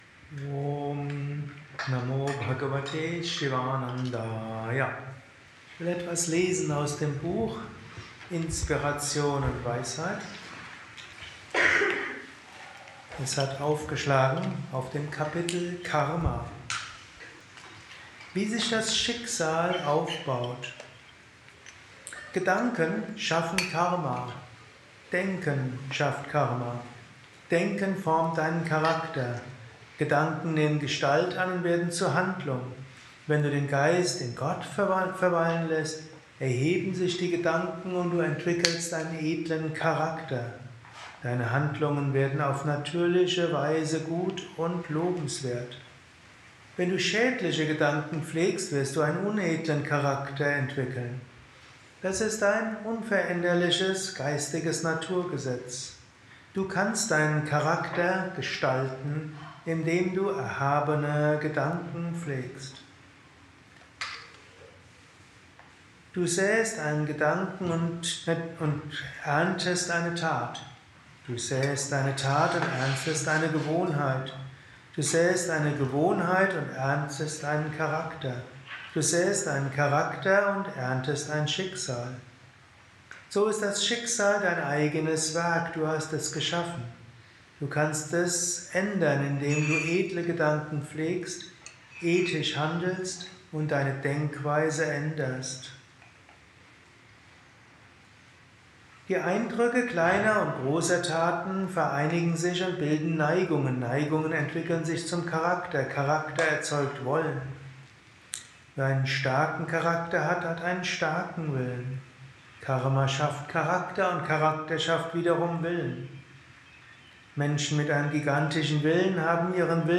Dies ist ein kurzer Vortrag als Inspiration für den heutigen Tag
während eines Satsangs gehalten nach einer Meditation im Yoga